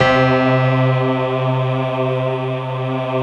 SI1 PIANO02L.wav